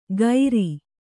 ♪ gairi